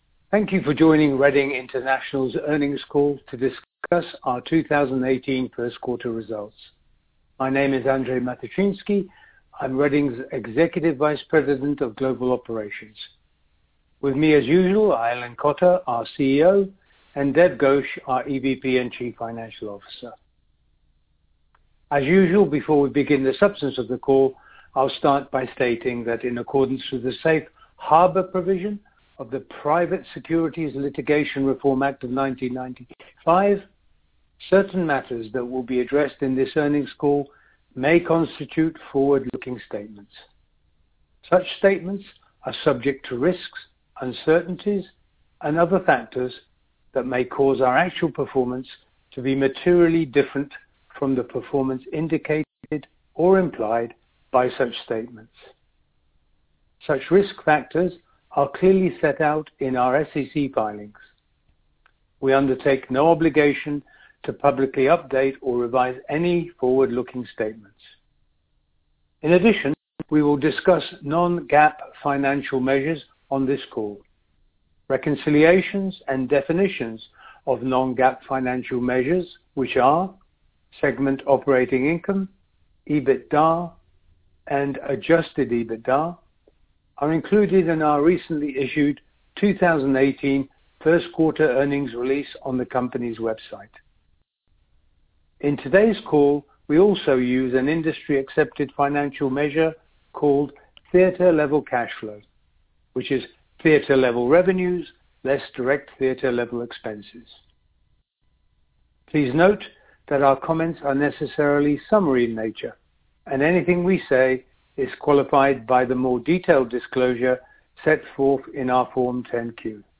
2018 – 1st Quarter Earnings Call
Question and answer session will follow the formal remarks.